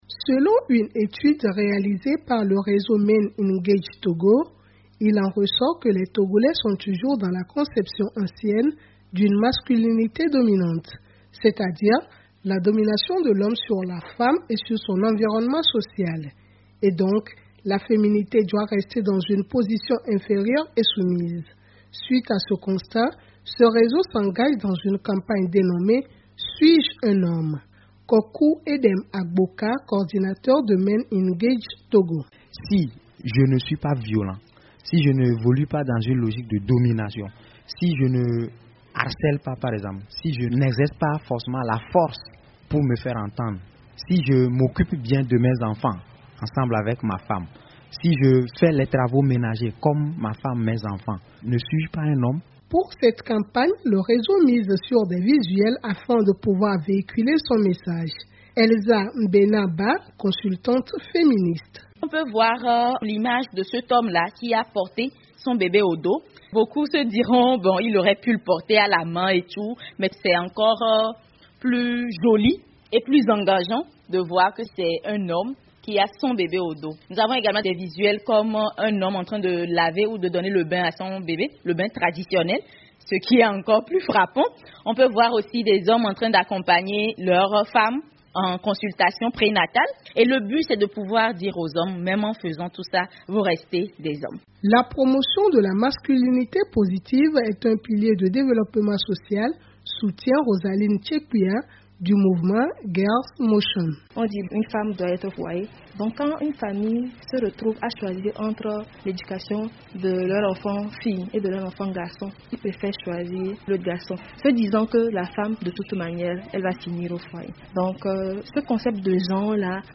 Du 11 au 31 janvier 2021, un réseau d’associations, dénommé MenEngage Togo, mène une campagne pour promouvoir l’équité genre. Axée sur la masculinité positive, cette campagne s’attaque aux stéréotypes pour susciter une prise de conscience. Reportage à Lomé de notre correspondante